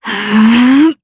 Valance-Vox_Casting8_jp.wav